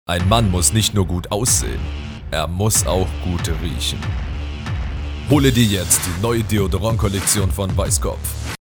Professioneller, deutscher, männlicher Sprecher mit tiefer, angenehmer, autoritärer und entspannter Stimme.
Sprechprobe: Werbung (Muttersprache):
Professional German male voiceover with a deep, pleasant, authoritative and calming voice.
Germanvoice_Commercial German.mp3